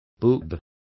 Complete with pronunciation of the translation of boobs.